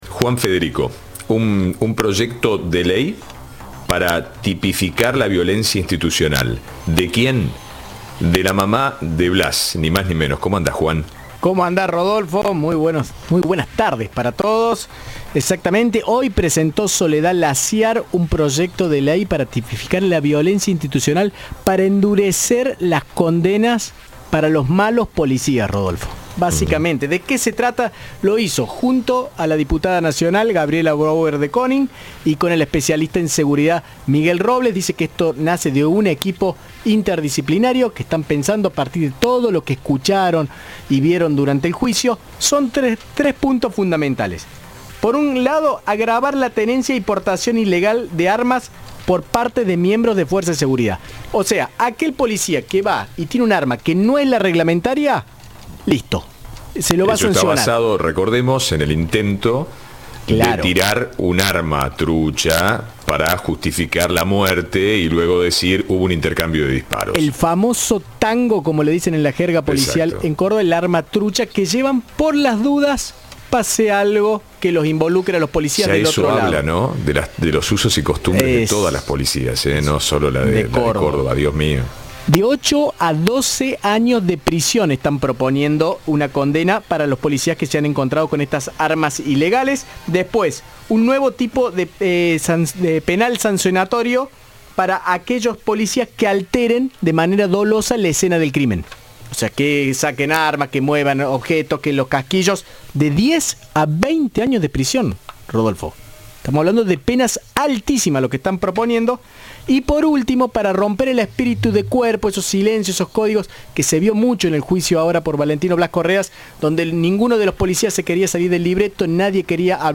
En ese sentido, en una conferencia celebrada en el Museo de Antopropologías se presentaron tres modificaciones al Código Penal.
Informe